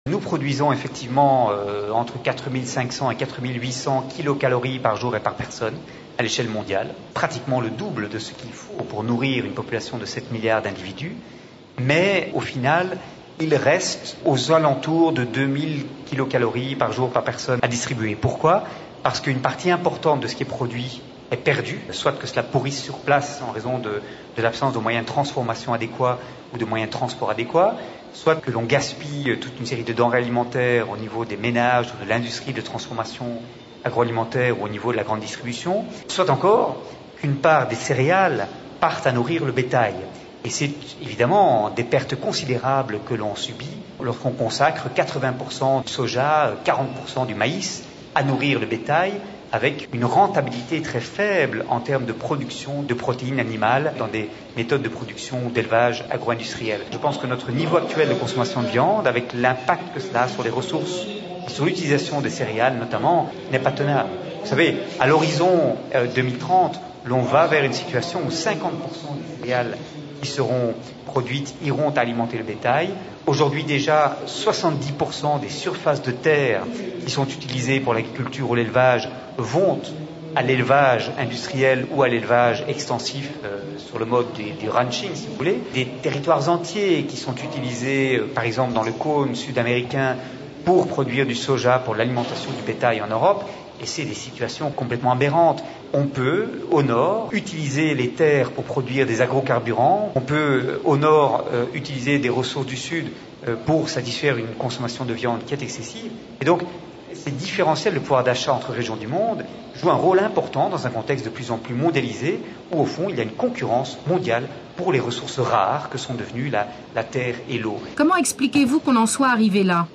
Texte intégral de l'entretien :